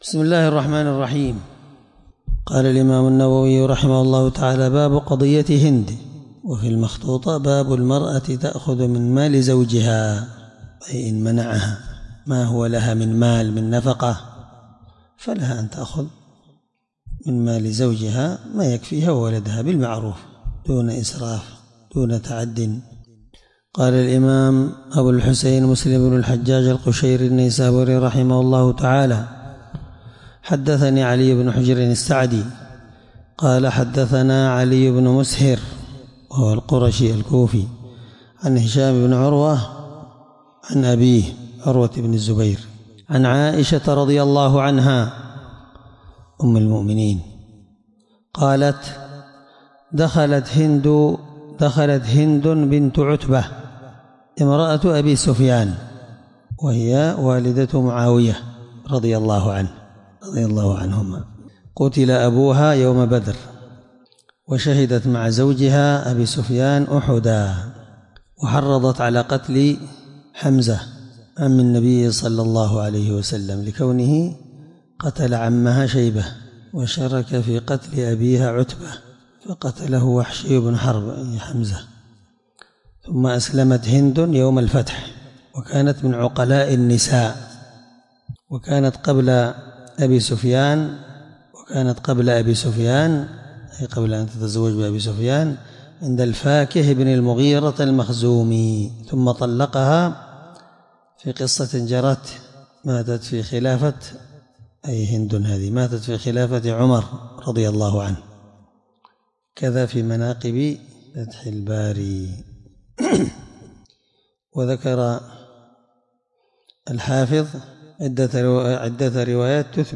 الدرس4من شرح كتاب الأقضية الحدود حديث رقم(1714) من صحيح مسلم